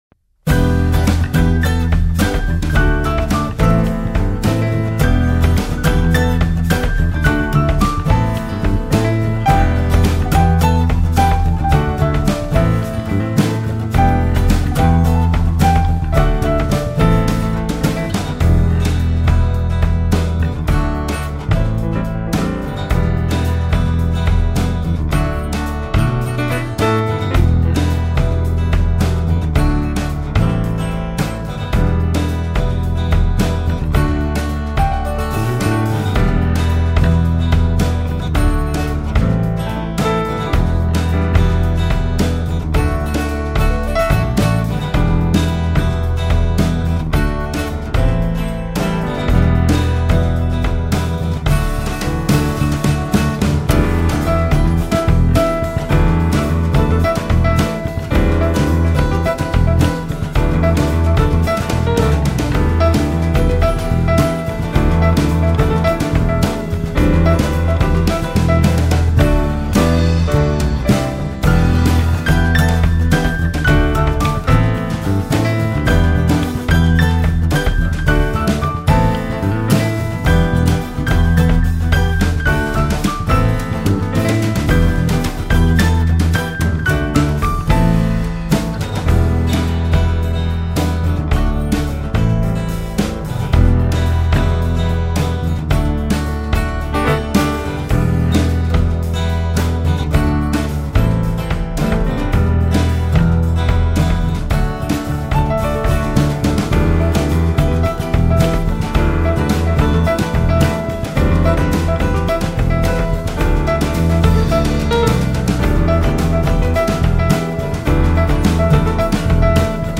. die etwas andere Coverband ...